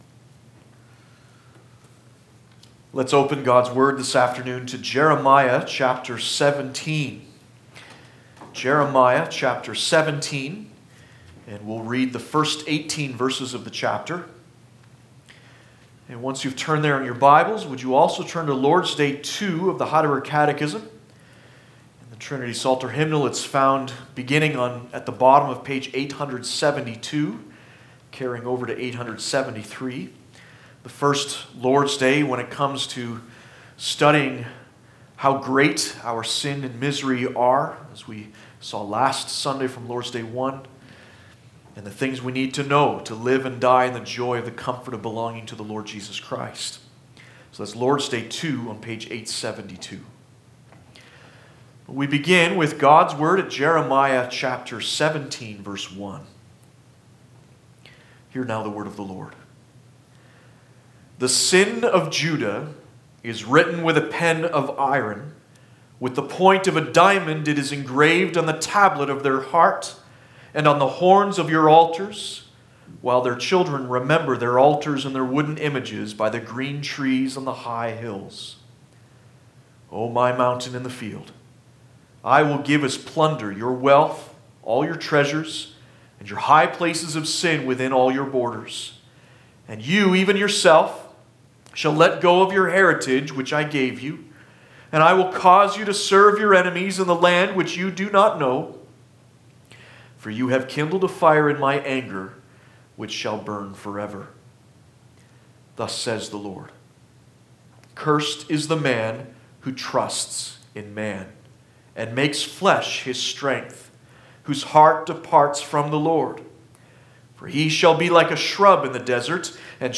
Passage: Jeremiah 17:1-18 Service Type: Sunday Afternoon